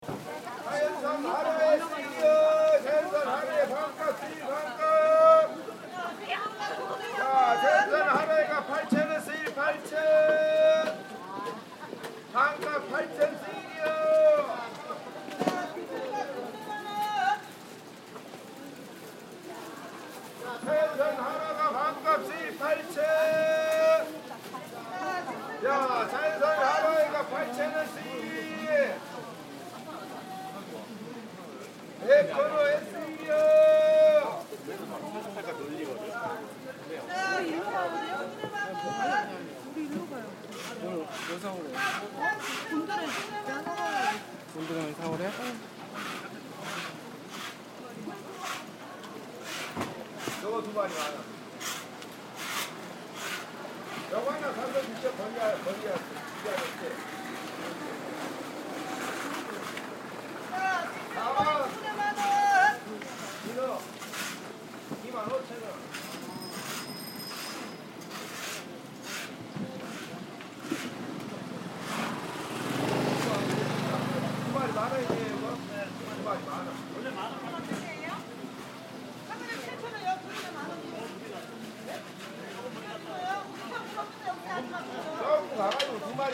Fishmonger calling out the day's discounts
South of the river, the Noryangjin Market is entirely devoted to seafood. Here is a fishmonger calling out the day's discounts.